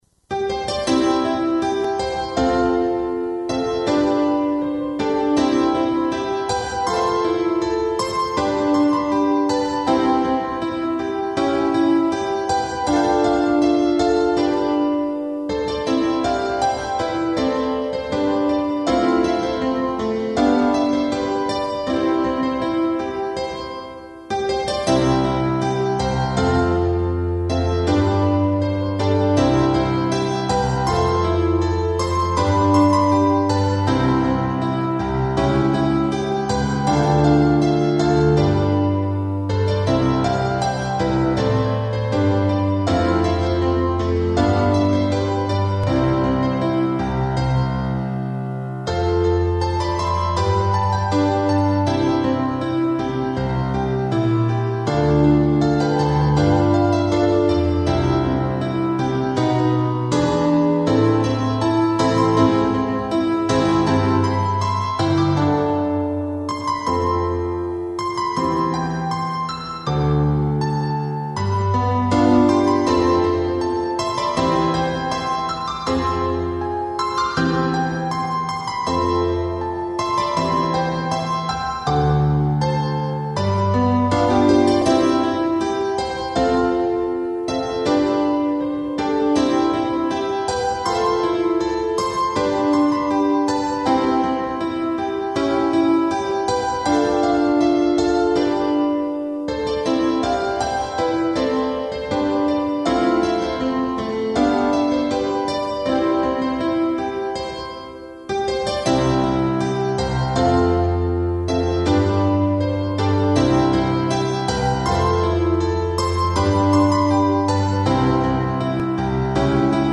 こちらでは、ゲーム内で使われているＢＧＭを何曲か、お聴かせしちゃいます。
雅イメージテーマのアレンジver.　。